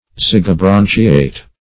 Search Result for " zygobranchiate" : The Collaborative International Dictionary of English v.0.48: Zygobranchiate \Zyg`o*bran"chi*ate\, a. (Zool.)